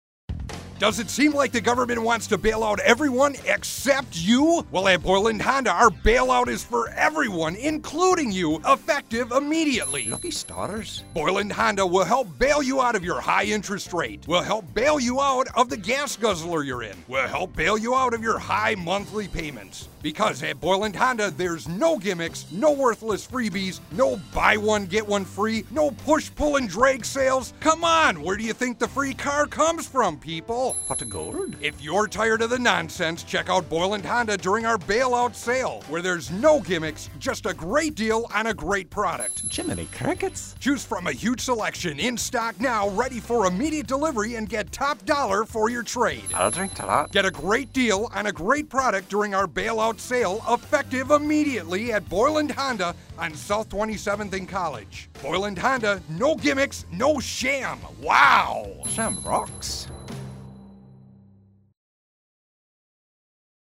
Boyland Honda Irish Bailout Radio Commercial